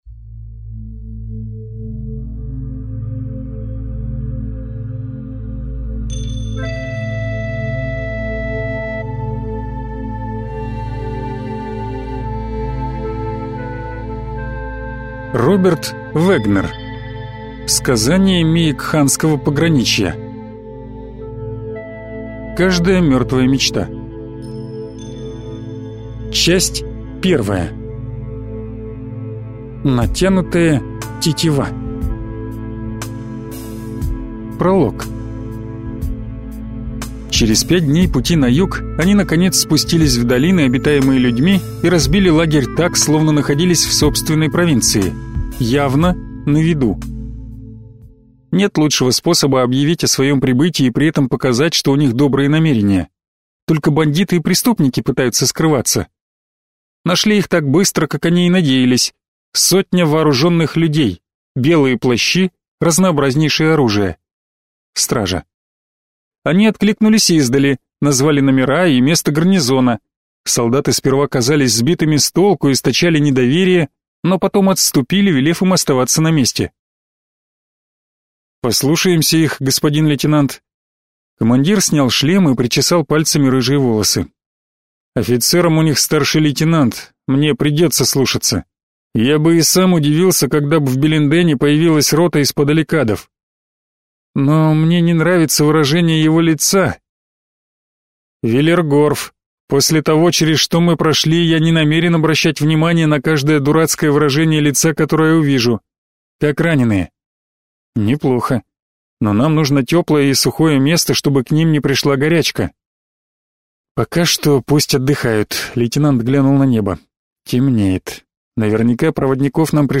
Аудиокнига Сказания Меекханского пограничья. Каждая мертвая мечта. Часть 1 | Библиотека аудиокниг